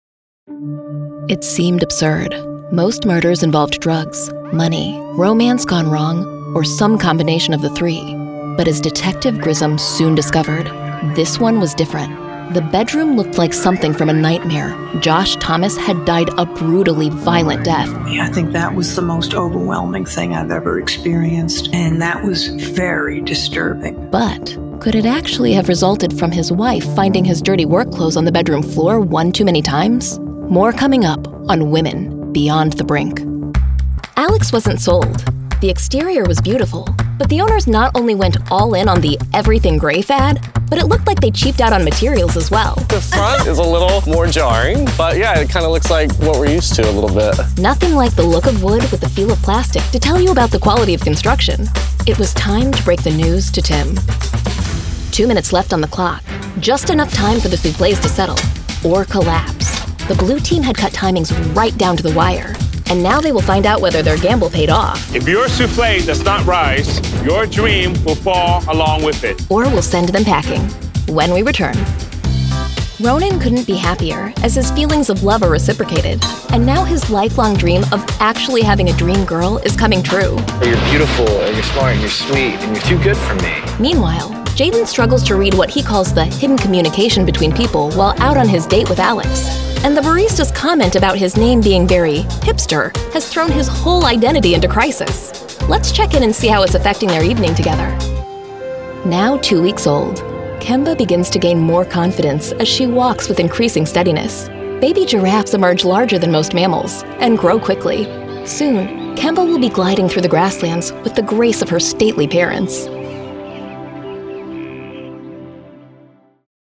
Voiceover Artist,
Sex: Female
Accents: US Midwest, US General American
Sennheiser MKH 416 microphone, Studiobricks One Plus VO Edition, Source Connect